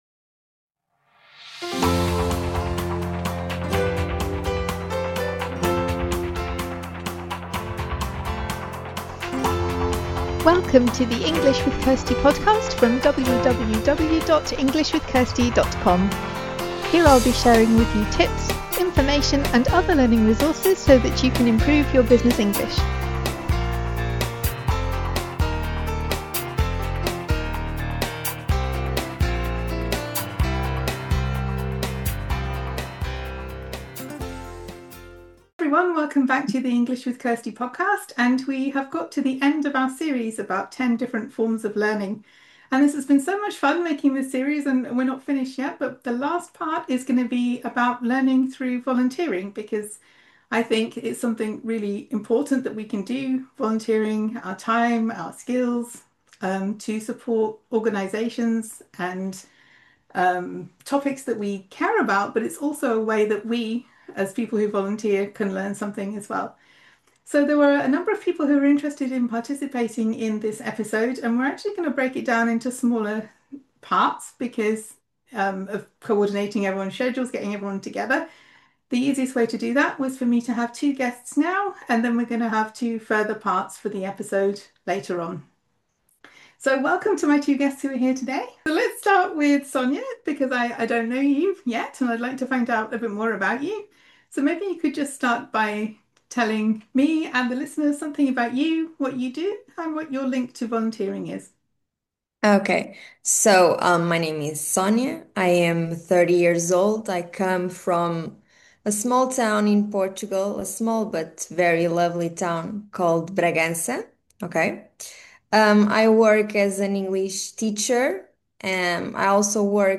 The final part of the series is about learning through Volunteering. I had a number of people who wanted to be involved, so in the end I decided to split this into three conversations.